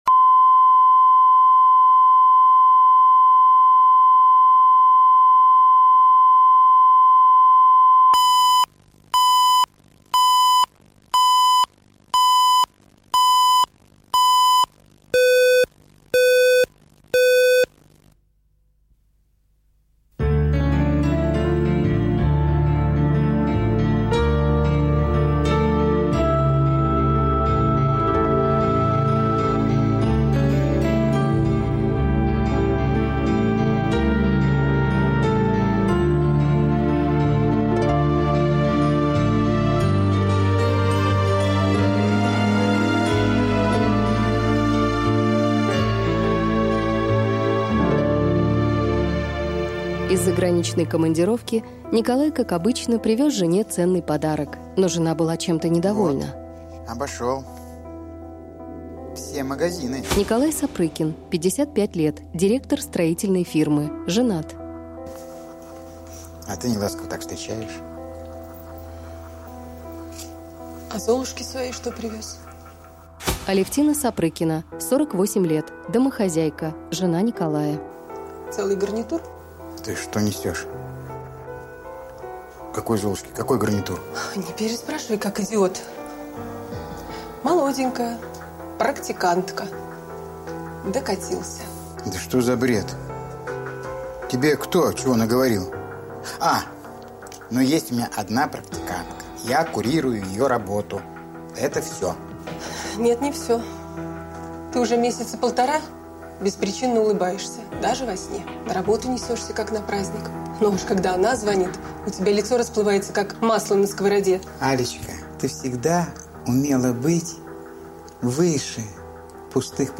Аудиокнига Мужчина в расцвете лет | Библиотека аудиокниг